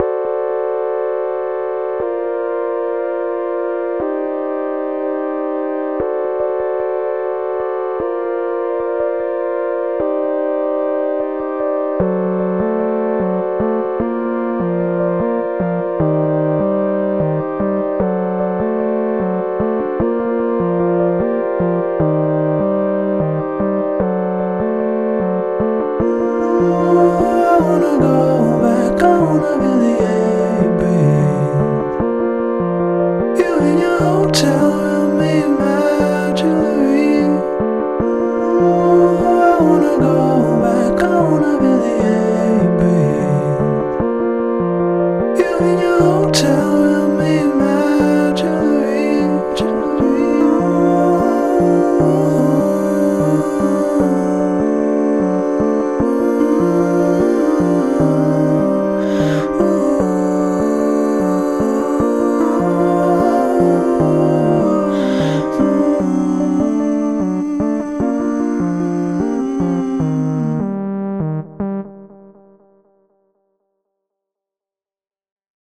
Introducing them one at a time, create a stack of four equal-length, non-drum loops.
With the loop stack running, add a fifth element on top—lead line, melody, whatever you please.
Allow the fifth element to end and remove the loops, avoiding the reverse order in which they were added.